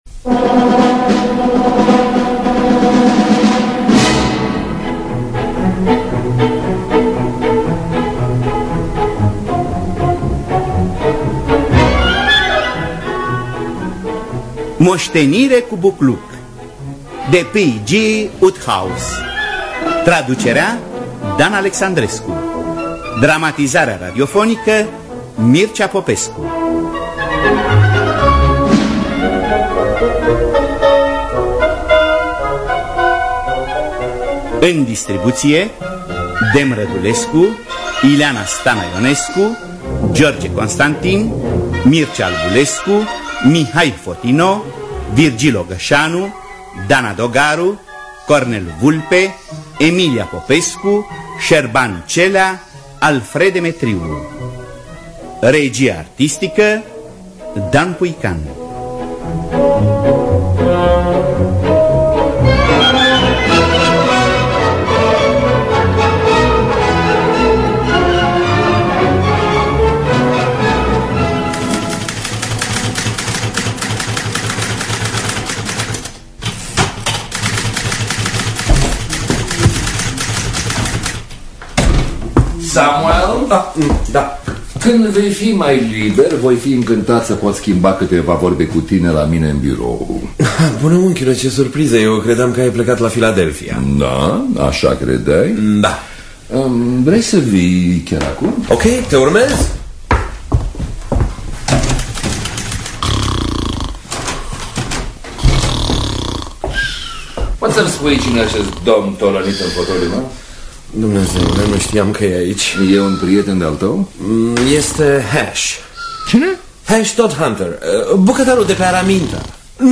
Dramatizarea radiofonică
Înregistrare din anul 1992.